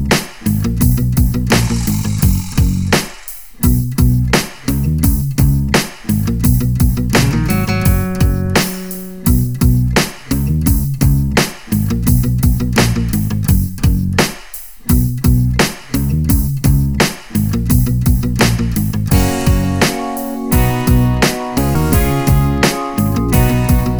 Reggae Version Reggae 3:54 Buy £1.50